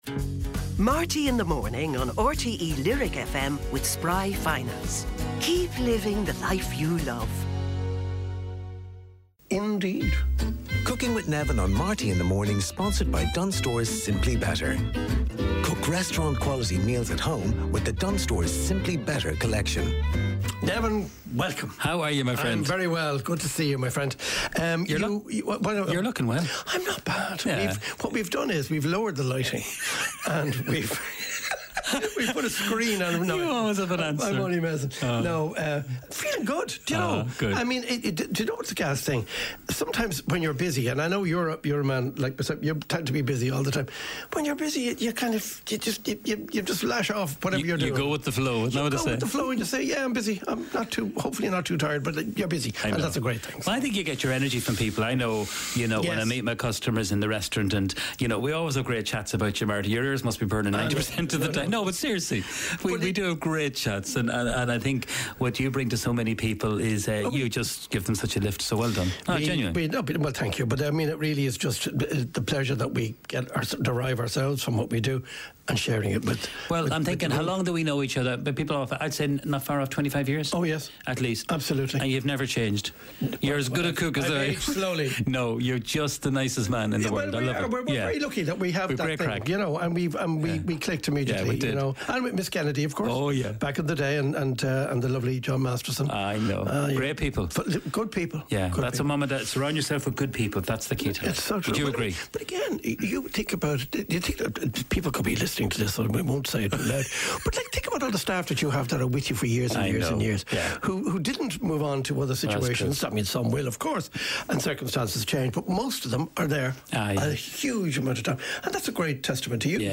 Highlights and special guest interviews and performances from Marty Whelan's breakfast show on RTÉ Lyric FM. Also includes recipes from the wonderful kitchen of Neven Maguire.